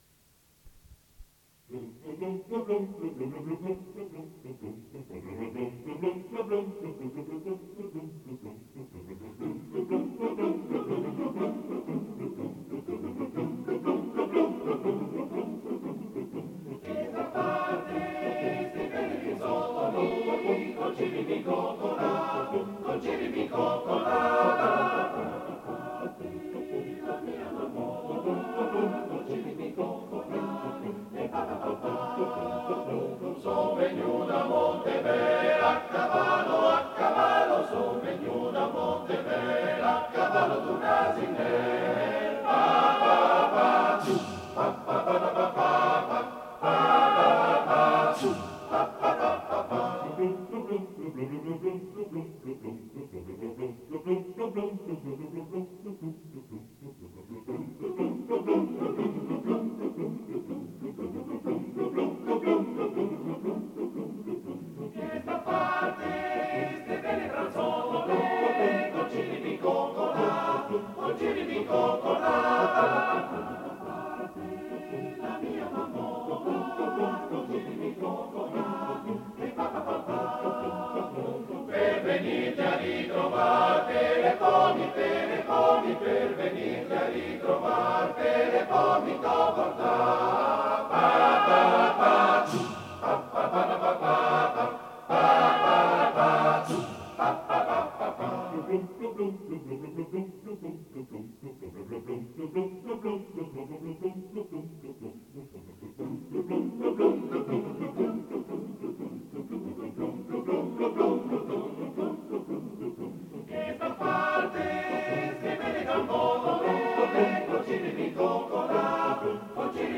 Esecutore: Coro Monte Cauriol